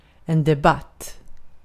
Ääntäminen
Synonyymit talks discourse agitate discuss contest contend Ääntäminen US UK : IPA : /dɪˈbeɪt/ Lyhenteet ja supistumat (laki) Deb.